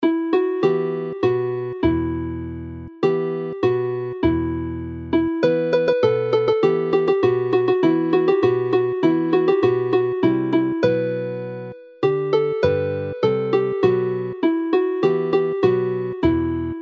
The tunes in this set have been chosen and pitched so that every note is playable on pipes which are generally limited to a single octave, eg. pibgorn, bagpipe.